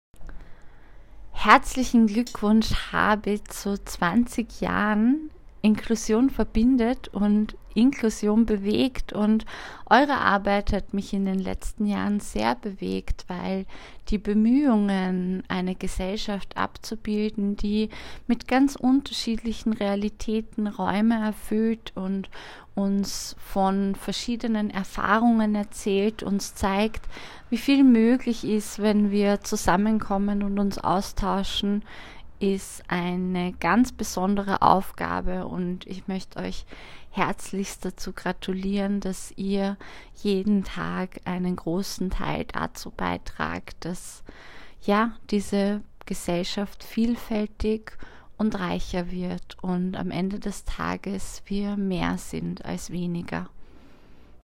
Bewegende Botschaften zum Thema Inklusion, gesprochen von Menschen aus Kunst, Kultur, Politik und Wissenschaft.